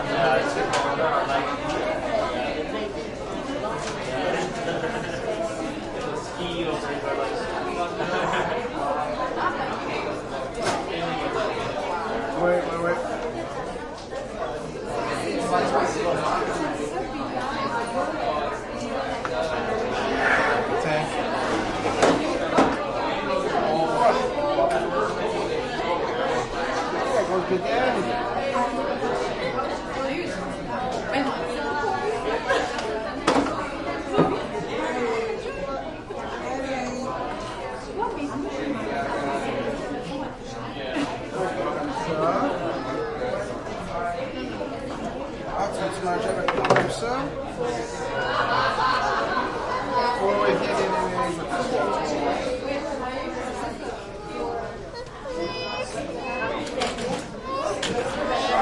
蒙特利尔 " 人群中大声活跃聊天的午餐时间，由自助餐厅的魁北克声音组成的大厅，加拿大电台蒙特利尔，Can
Tag: 蒙特利尔 大厅 响亮 食堂 健谈 活泼 午餐时间 魁北克 INT 人群 铺有地毯 声音 加拿大